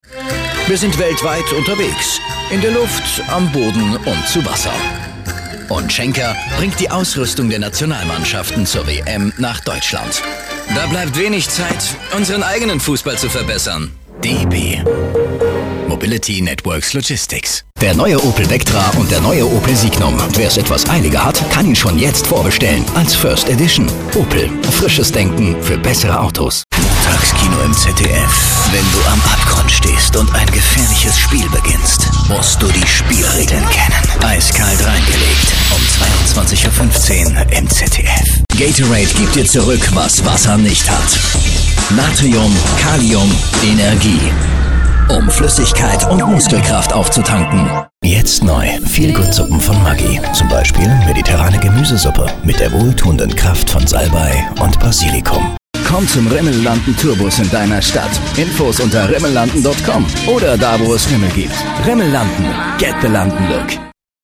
Profi Sprecher deutsch.
Sprechprobe: Werbung (Muttersprache):